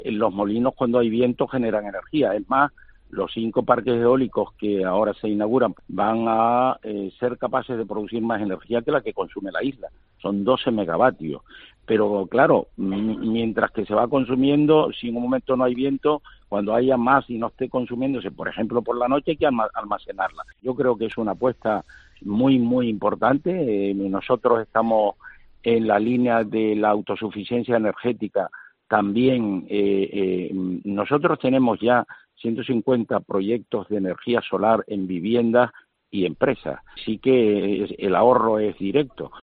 Casimiro Curbelo, presidente del Cabildo de La Gomera